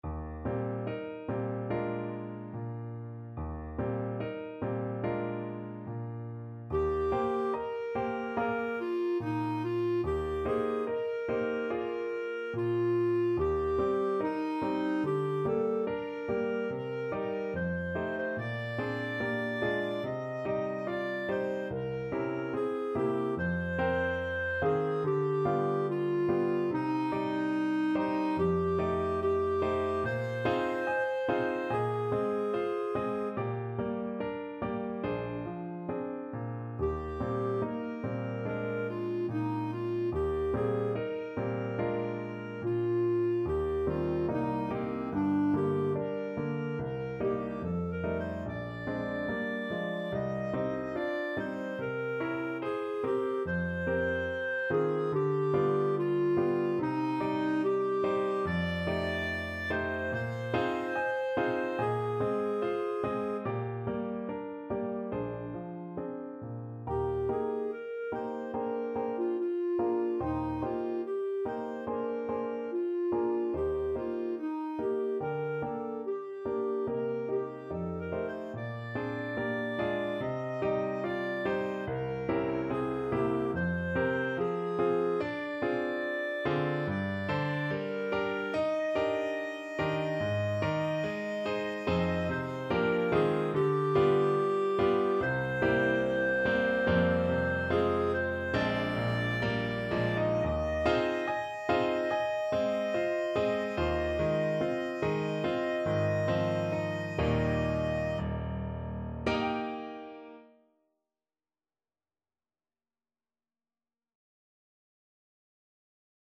Clarinet
4/4 (View more 4/4 Music)
Eb major (Sounding Pitch) F major (Clarinet in Bb) (View more Eb major Music for Clarinet )
~ = 72 In moderate time
Classical (View more Classical Clarinet Music)